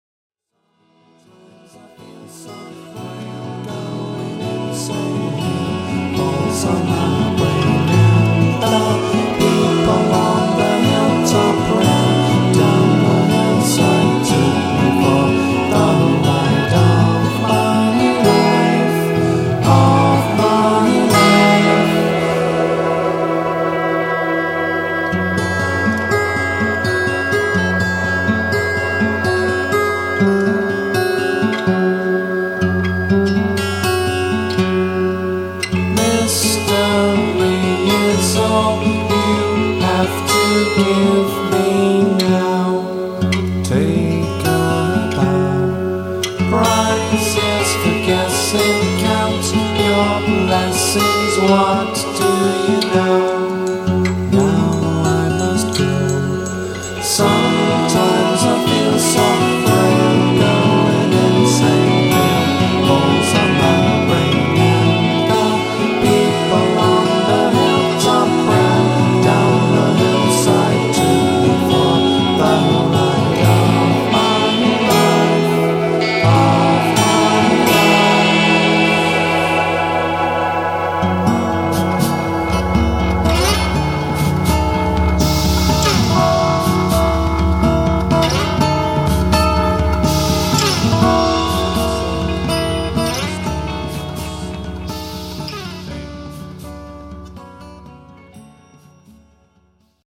Caressing musicianship, tunes to melt the hardest heart.